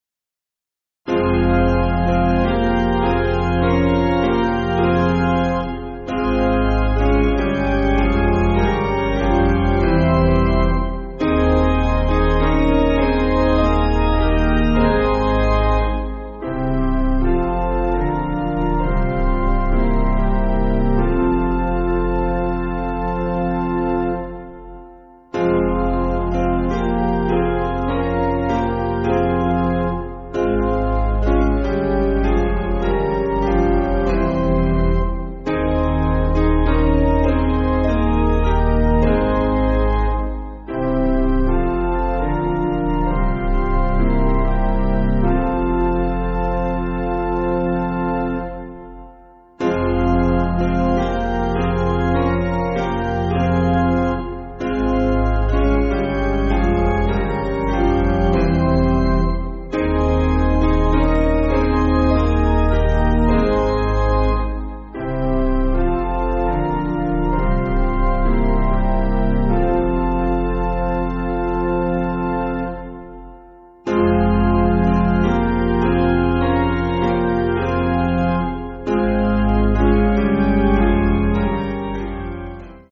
Basic Piano & Organ
(CM)   5/Fm